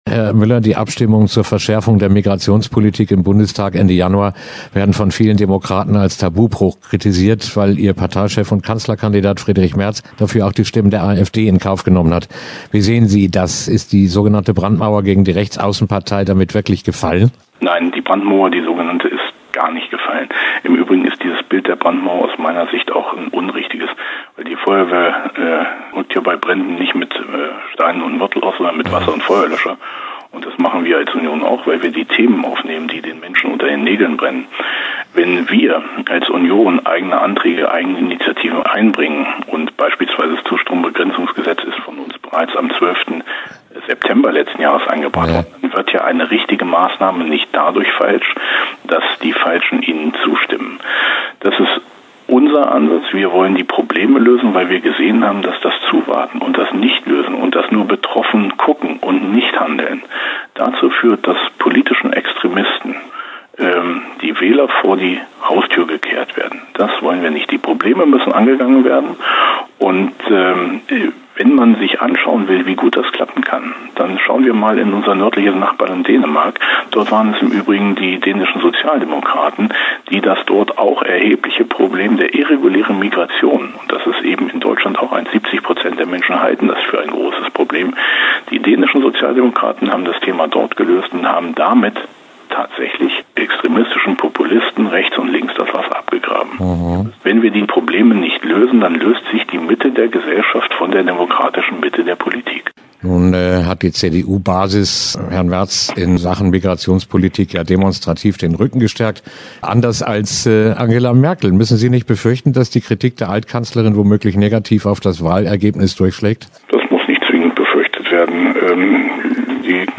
Direktkandidaten zur Bundestagswahl: Der Braunschweiger CDU-Abgeordnete Carsten Müller im Gespräch - Okerwelle 104.6
Interview-Wahl-2025-Mueller.mp3